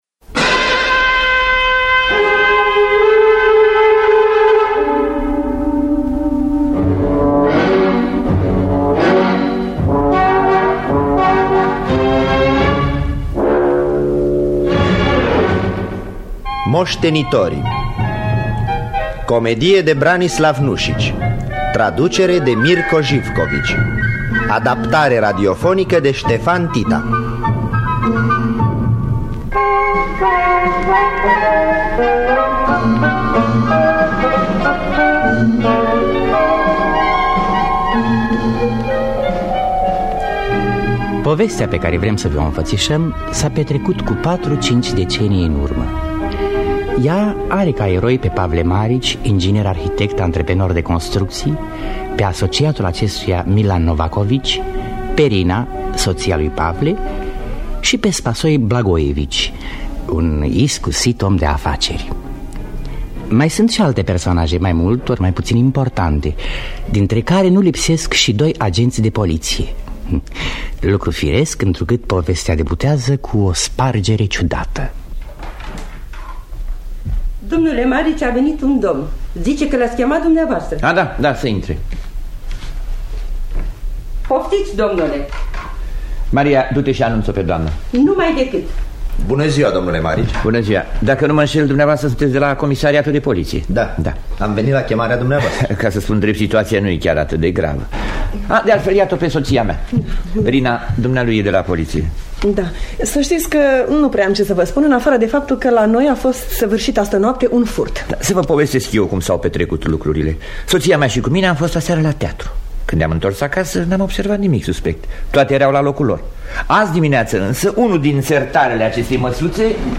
Moştenitorii de Branislav Nuşici – Teatru Radiofonic Online